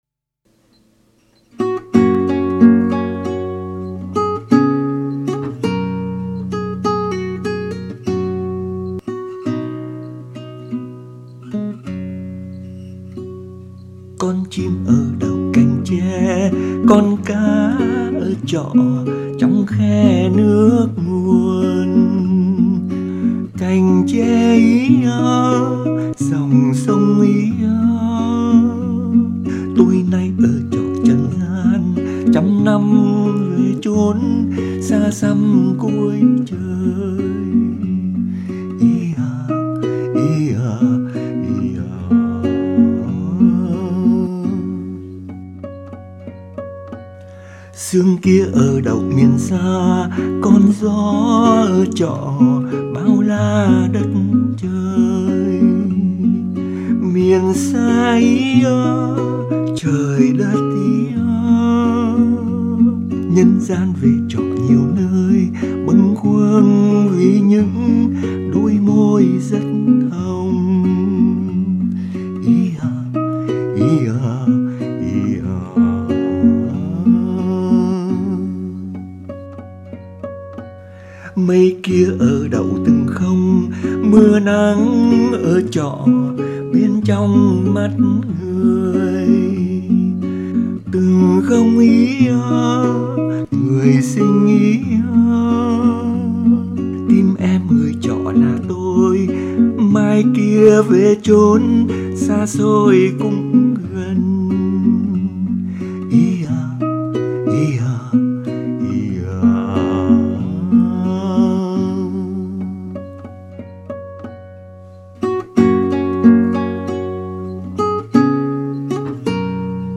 đàn và hát